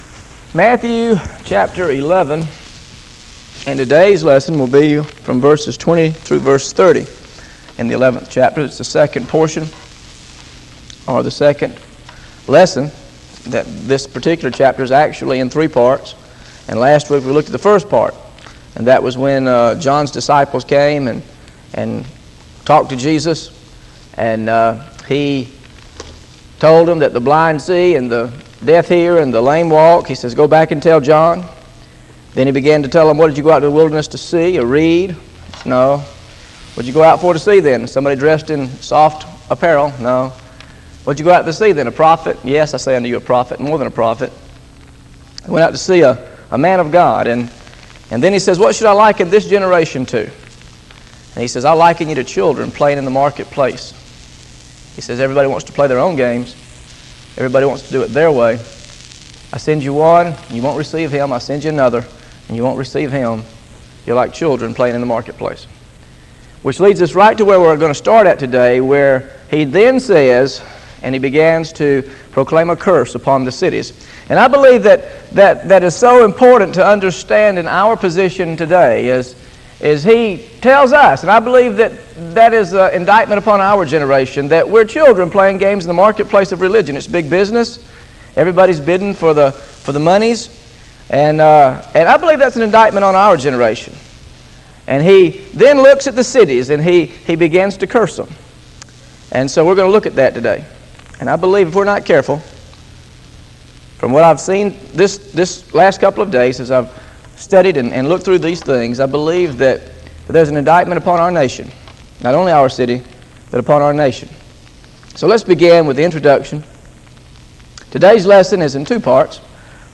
GOSPEL OF MATTHEW BIBLE STUDY SERIES This study of Matthew: Matthew 11 20-30 How to Discern God’s Judgment on Cities is part of a verse-by-verse teaching series through the Gospel of Matthew.